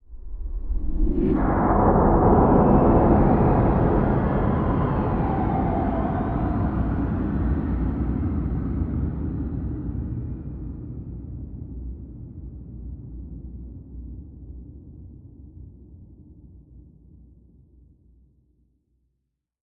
shooterFangJumps.ogg